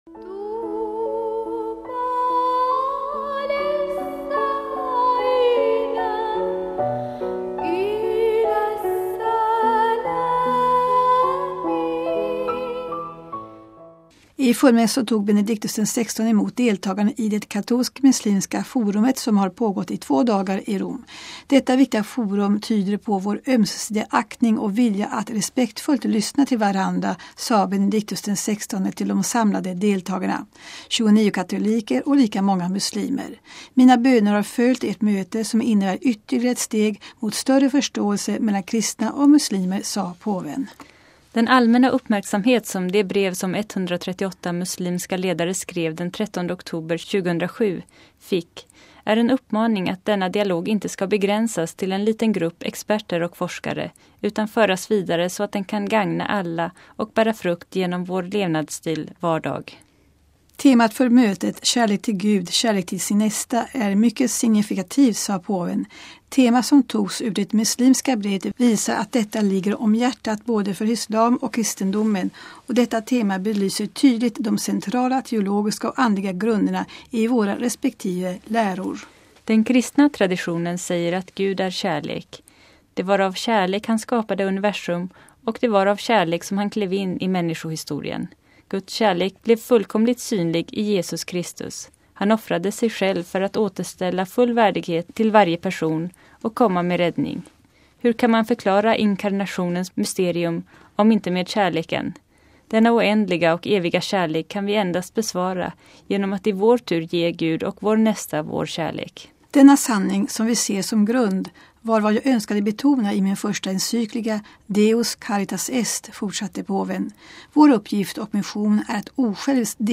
Uppgiften att älska Gud och vår nästa förenar oss. Benedictus XVI talar till deltagarna vid det katolsk-muslimska forumet
I förmiddags tog Benedictus XVI emot deltagarna i det katolsk-muslimska forumet som har pågått under två dagar i Rom.